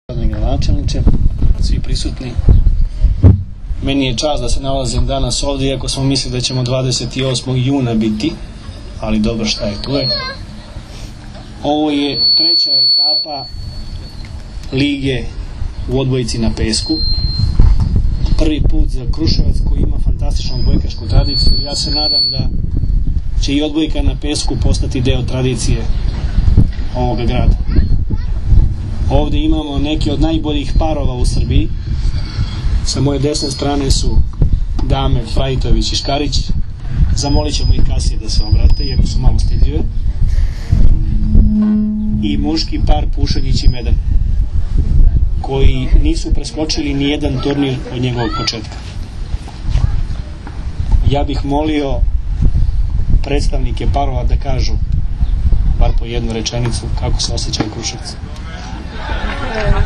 IZJAVA VLADIMIRA GRBIĆA 1